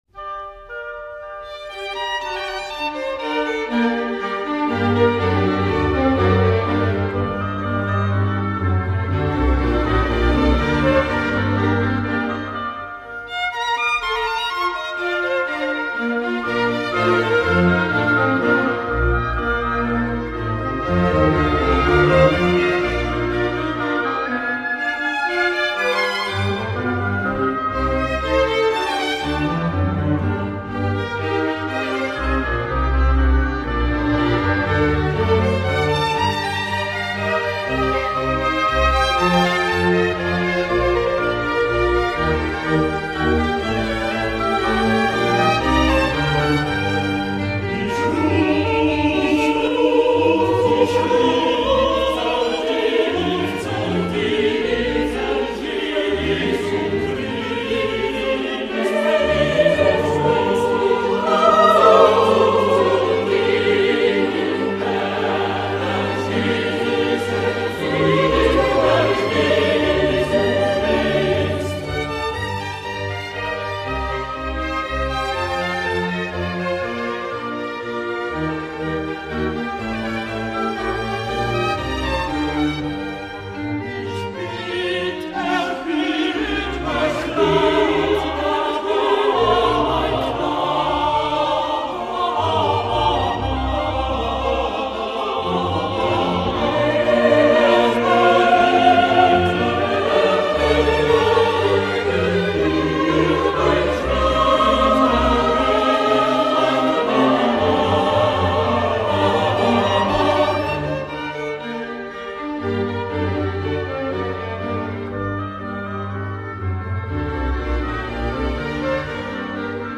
BACH, cantate bwv 177 Ich ruf zu dir, Herr Jesu Christ - RAPHAEL, Dieu le pere benissant.mp3